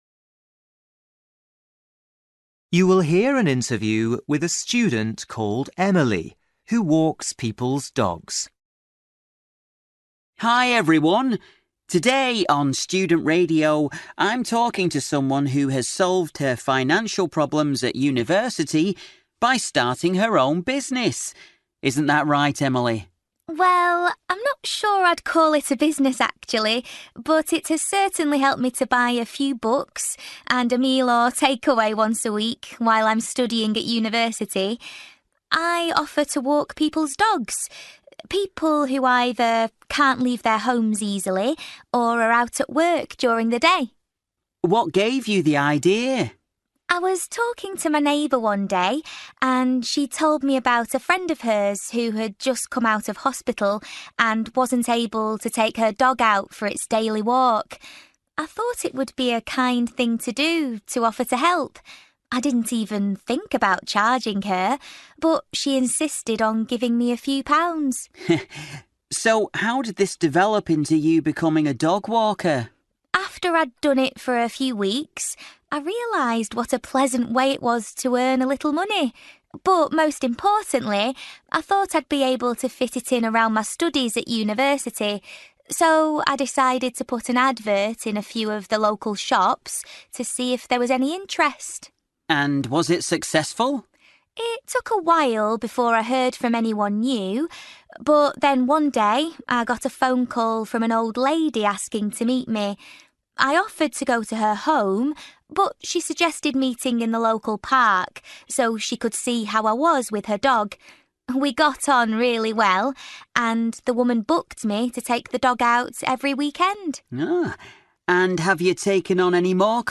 Bài tập trắc nghiệm luyện nghe tiếng Anh trình độ trung cấp – Nghe một cuộc trò chuyện dài phần 10